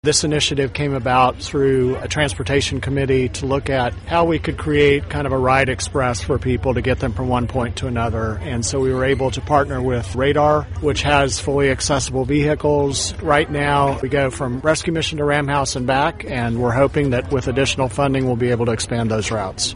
Roanoke Mayor Joe Cobb was at today’s official announcement about RIDE: